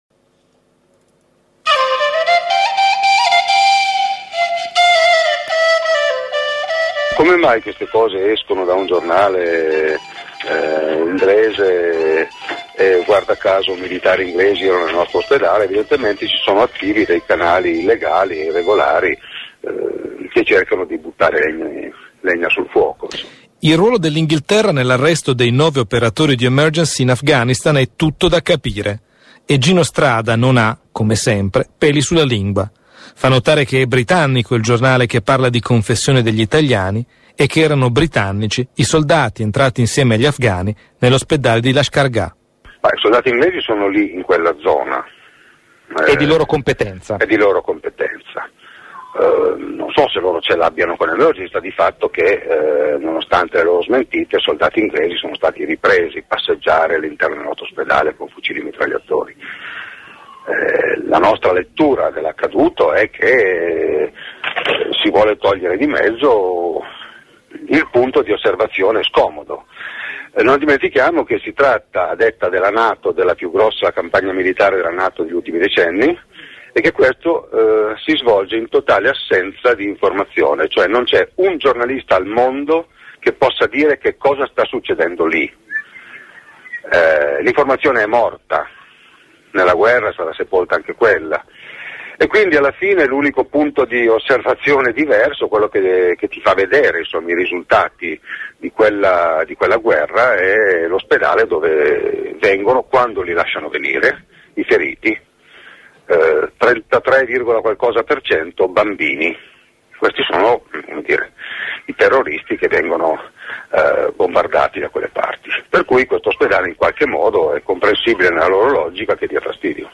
Abbiamo sentito Gino Strada intervistato